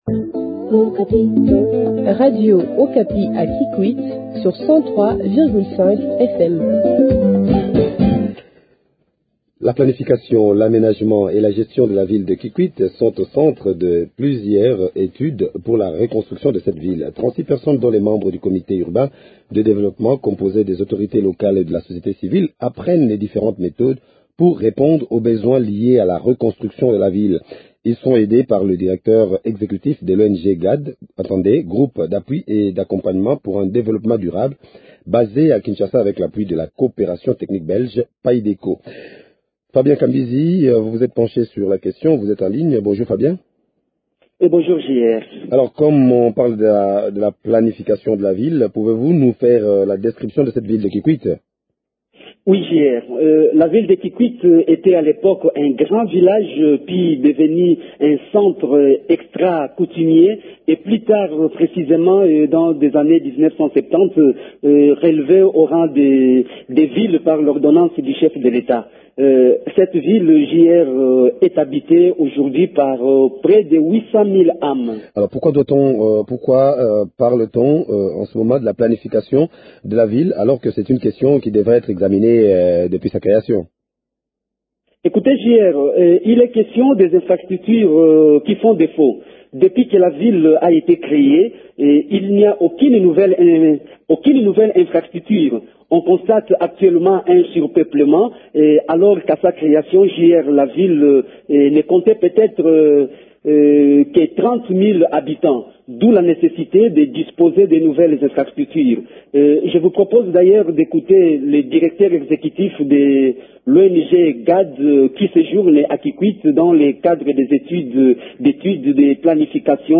Les détails dans cet entretien que vous propose